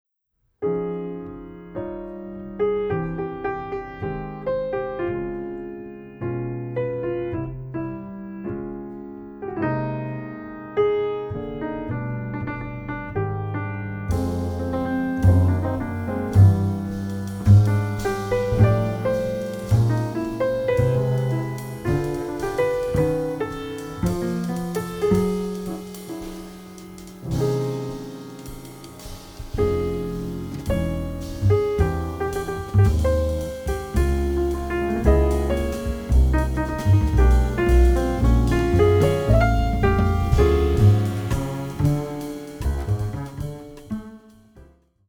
Recorded on July 13.2025 at Studio Happiness